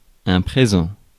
Ääntäminen
France: IPA: [pʁe.zɑ̃]